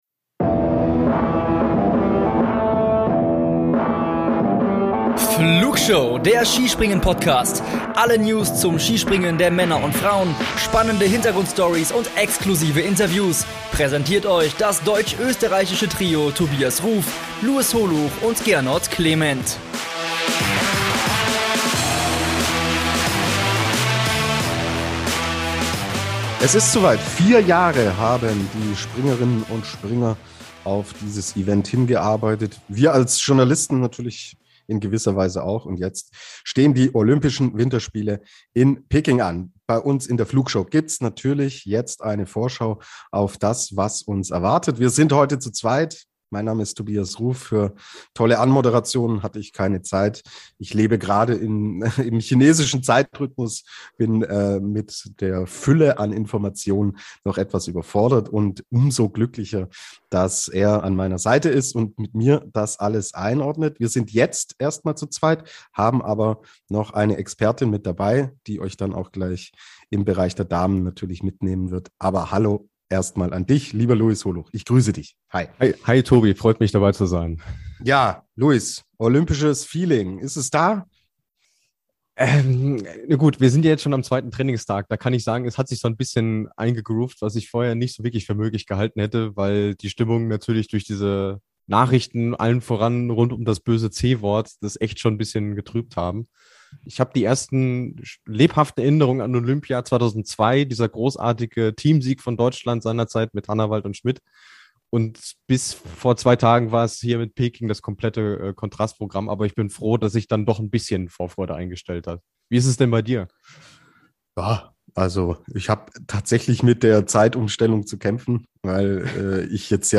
So außergewöhnlich das Ereignis, so ungewöhnlich die Aufnahme: Terminlich bedingt mussten wir diese in zwei Teilen aufnehmen.